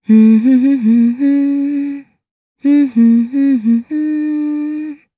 Seria-Vox_Hum_jp.wav